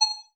Holographic UI Sounds 4.wav